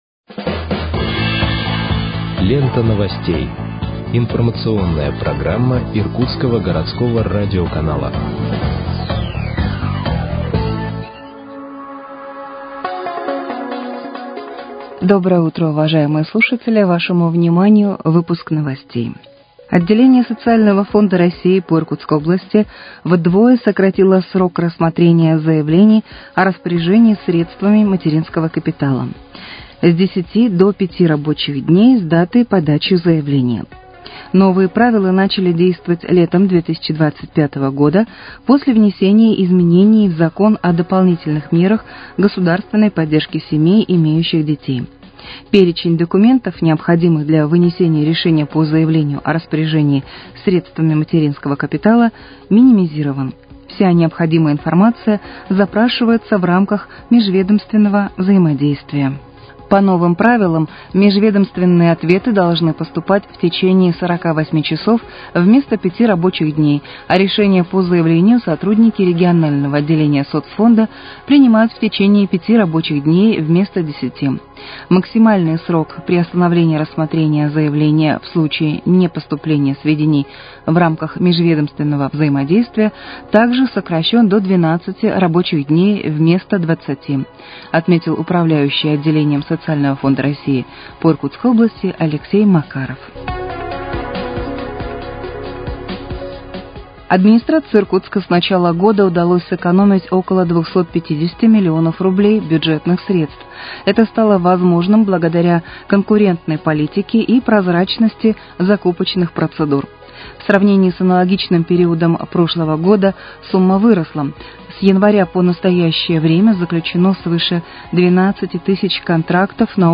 Выпуск новостей в подкастах газеты «Иркутск» от 13.08.2025 № 1